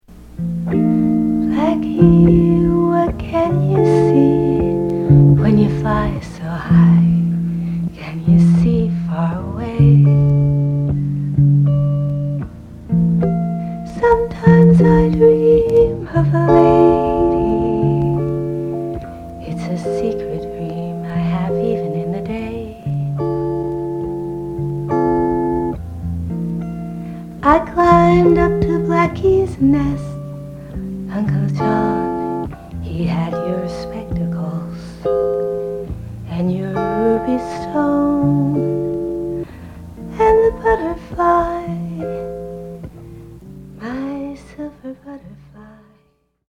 じっとりと湿った夜のメランコリー。
即興　フォーク